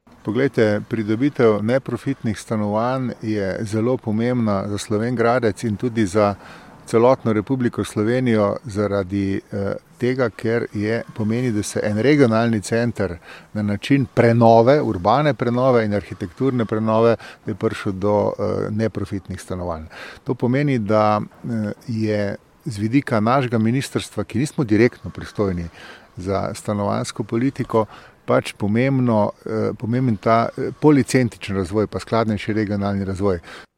Prireditve ob predaji se je udeležil tudi državni sekretar Ministrstva za naravne vire in prostor Miran Gajšek.